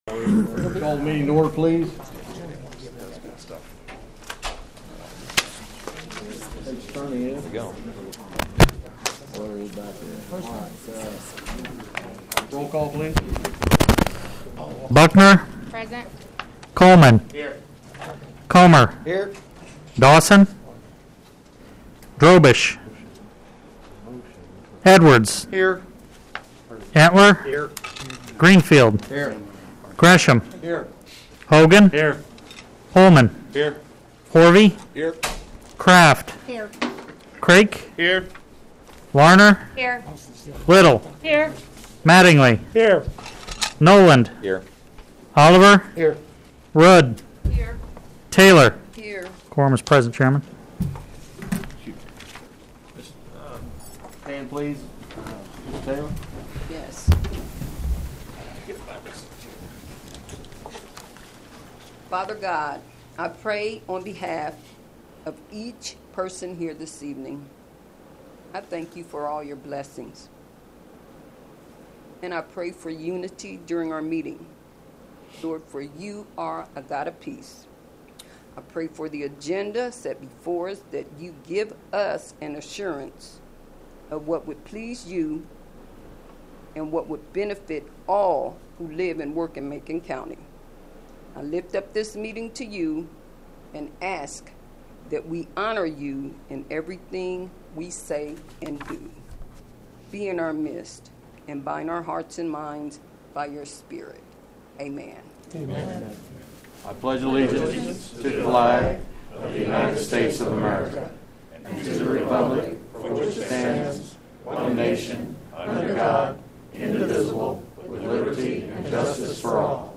Public comments start at 9:00.
Most of the remainder of the meeting is questions, mostly lack of answers, and discussion.